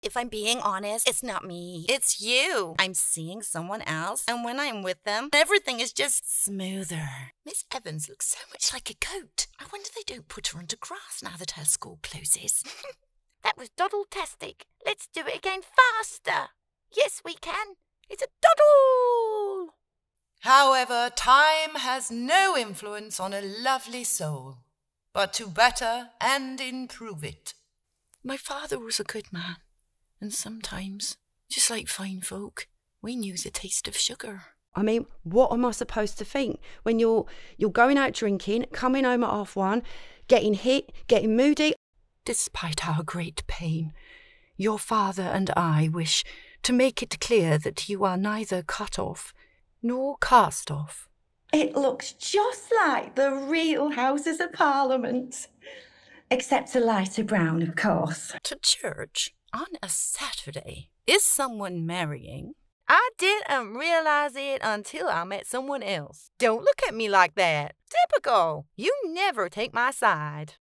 • Native Accent: Black Country
• Home Studio